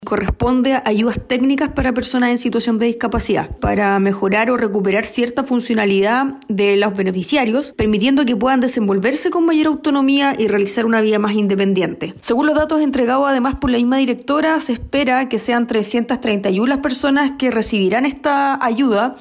En tanto, la consejera Tania Valenzuela, señaló que serán 331 personas las que recibirán estas ayudas técnicas para mejorar funcionalidad y otorgar elementos que logran una vida con mayor independencia.